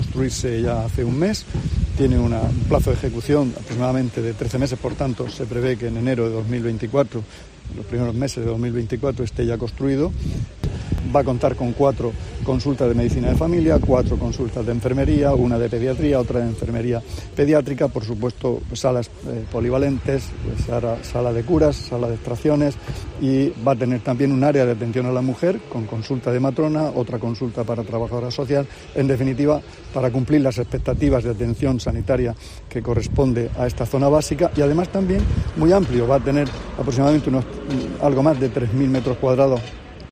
Juan José Pedreño, consejero de Salud